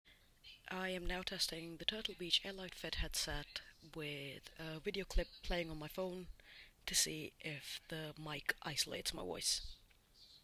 This is a bi-directional mic, meaning it picks up sound from the front and back, which is ideal for gaming as it focuses on your voice while minimizing background noise.
To test this, I played the TV show Parks and Recreation loudly on my phone (about 60% volume) to see if the mic isolates my voice. While you can faintly hear the character played by Kathryn Hahn, my voice is still clear and loud, which is impressive for such an affordable headset.
Mic sample 2